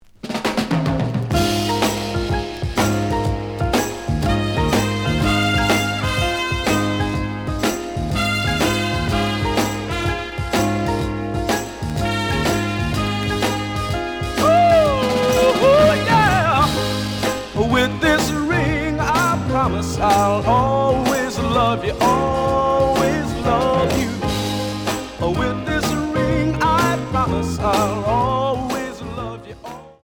試聴は実際のレコードから録音しています。
●Genre: Rhythm And Blues / Rock 'n' Roll
●Record Grading: VG+ (盤に若干の歪み。多少の傷はあるが、おおむね良好。)